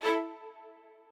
strings14_4.ogg